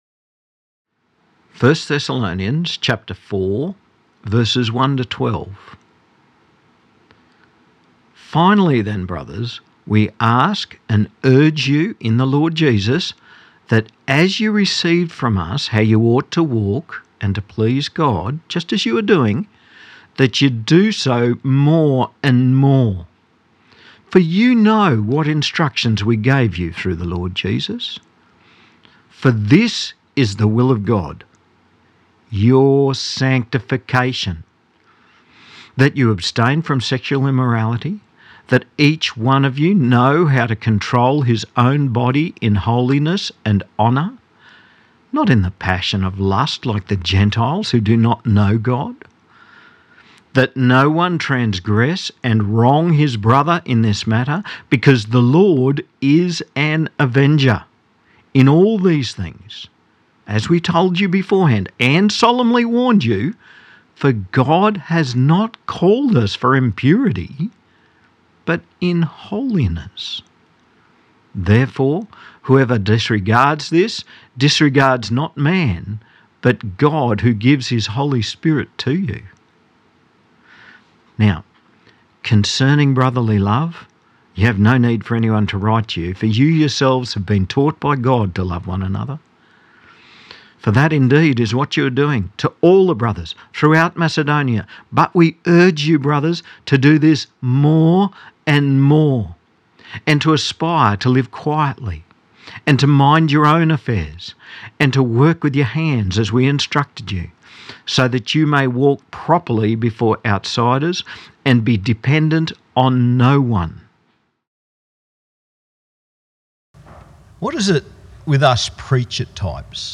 1 Sermon 2025-02-23 1 Thessalonians 2:17-3:13 39:44